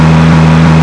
cardriving.wav